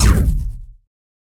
mech_shield_deflect.ogg